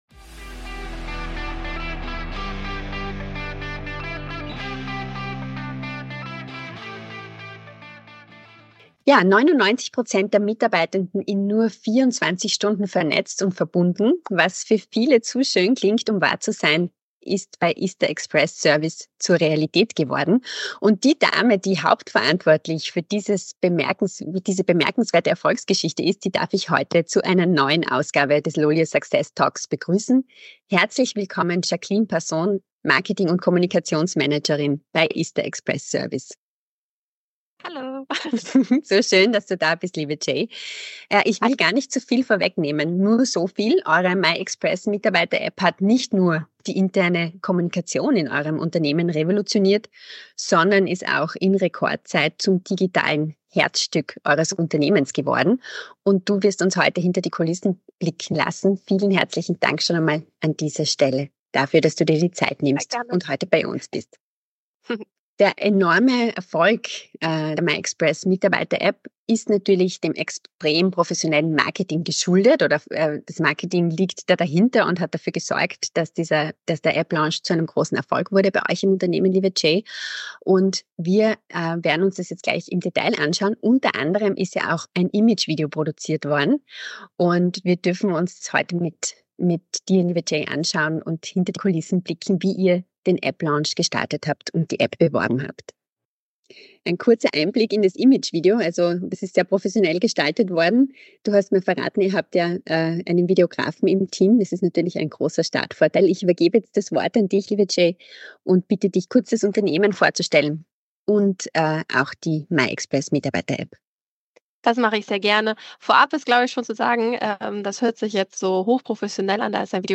Success Talks by LOLYO ist ein Podcast mit ausgewählten Talkgästen und spannenden Themen rund um die unternehmensinterne Kommunikation und Mitarbeiter-Apps.